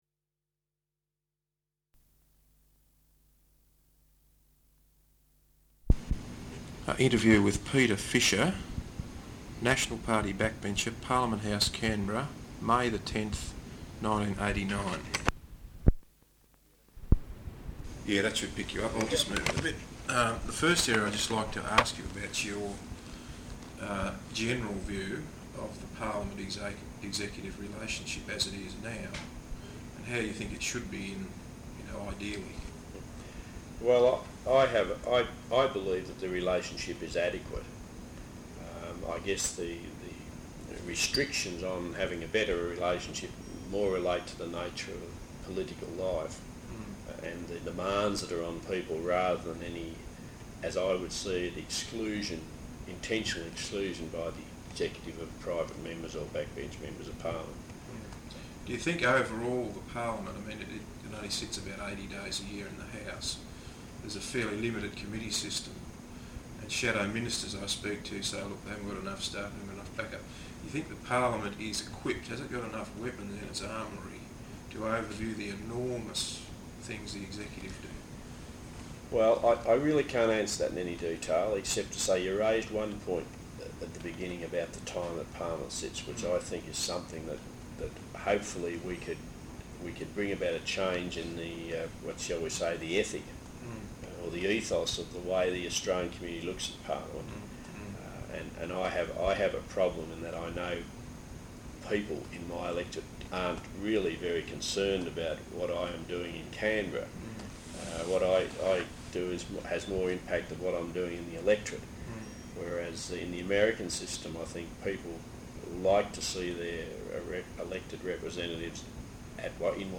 Interview with Peter Fisher, National Party backbencher. Parliament House, Canberra.